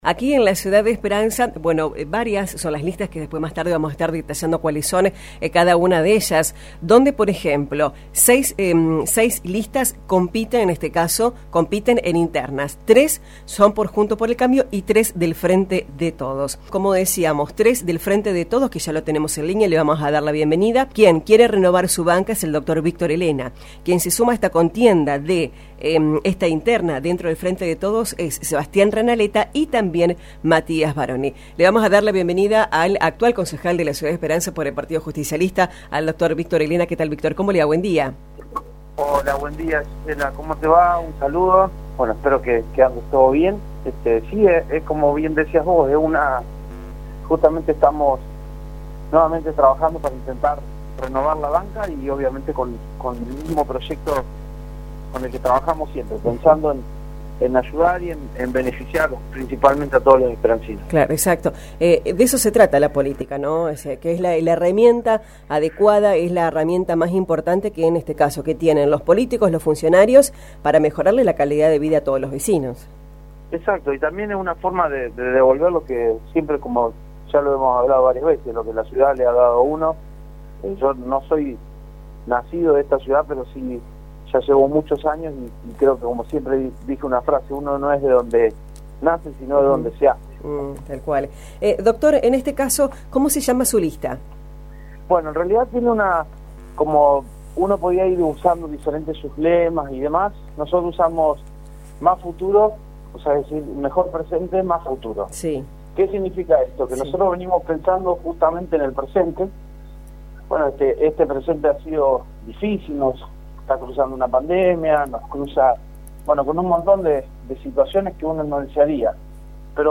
El actual concejal Dr.Víctor Elena en “Una Mañana Perfecta” presentó su lista “Mejor presente, más futuro” con el objetivo de mantener el dialogo con el vecino y seguir construyendo desde el concejo municipal un mejor futuro para todos.